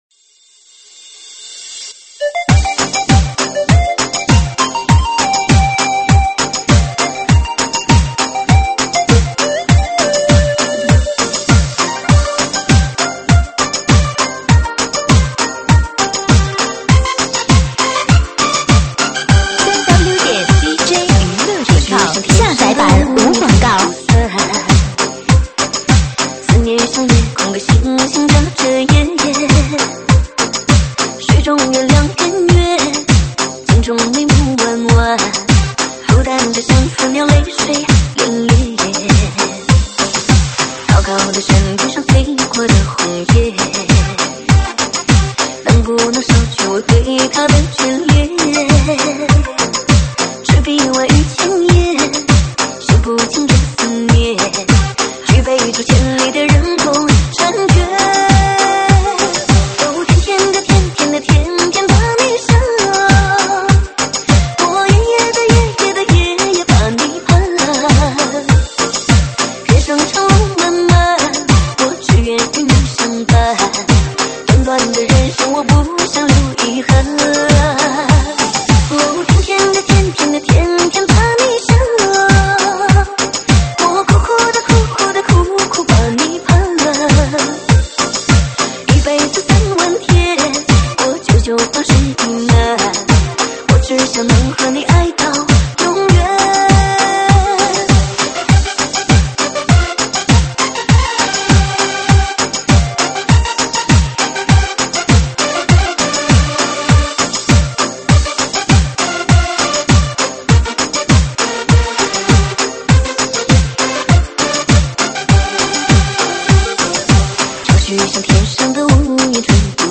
舞曲编号：75019